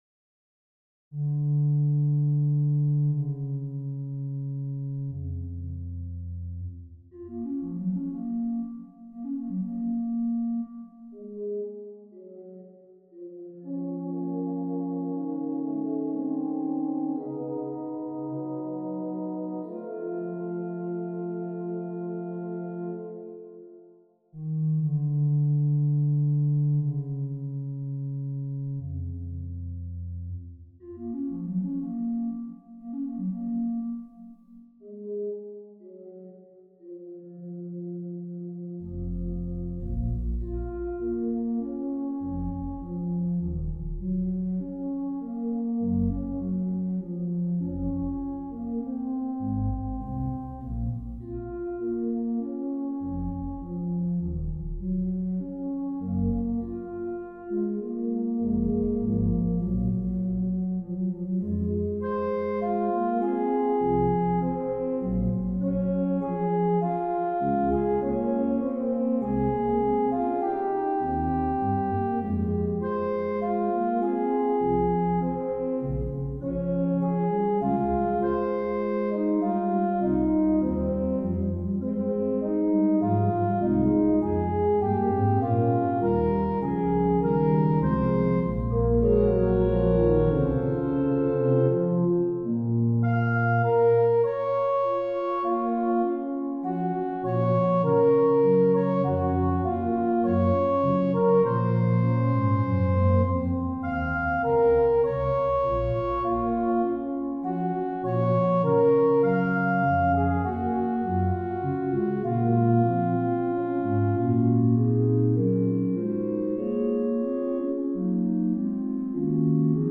for organ
From a major to minor third of the scale begins the opening gestures, as the fugue begins in the minor and then subsequently moves to the major key. Later alteration to the subject slightly changes the use of counterpoint and tonal domains, and small coda rooted on the subdominant complete the piece.